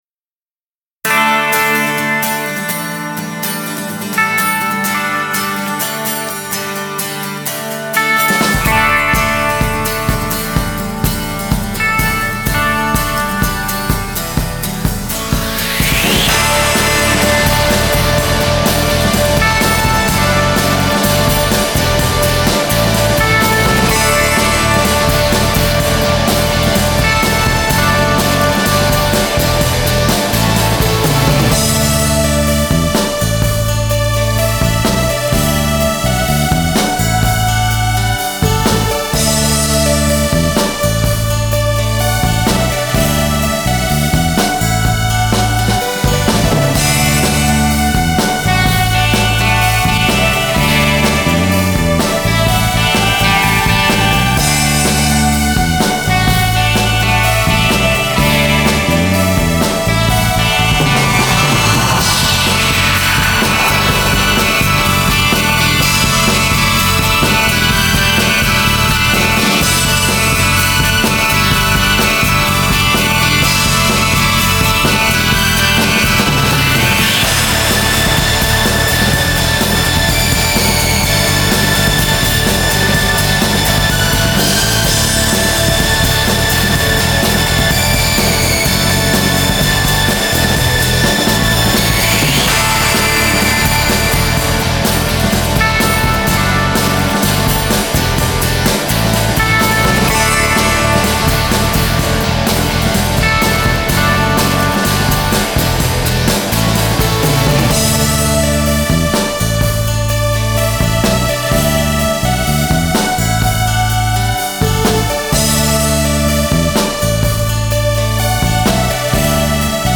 ノリの良いシンセベースが特長のエレクトロ系の楽曲です
最近流行のサンプリングCDでの曲作りで試しに作った楽曲です。